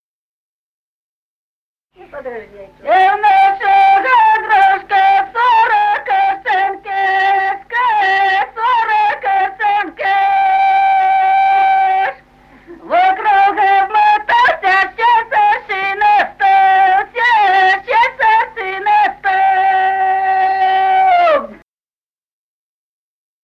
Народные песни Стародубского района «И в нашего дружка», свадебная.
с. Остроглядово.